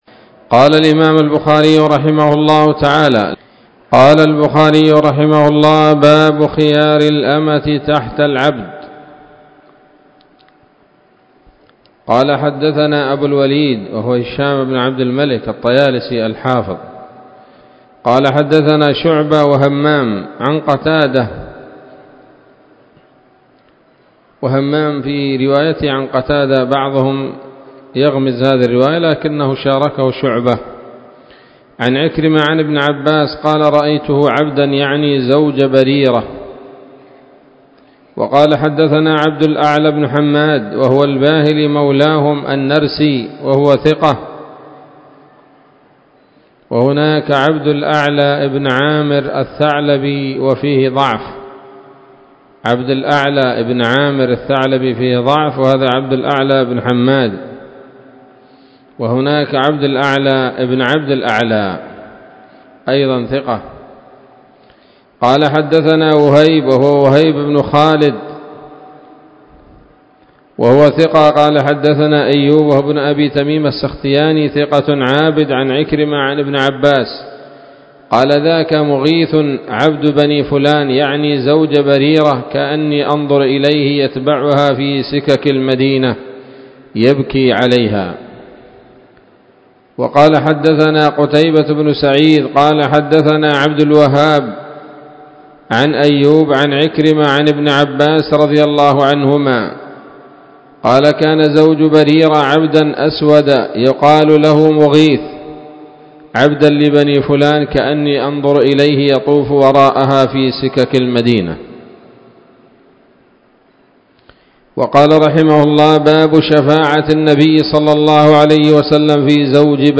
الدروس العلمية شروح الحديث صحيح الإمام البخاري كتاب الطلاق من صحيح البخاري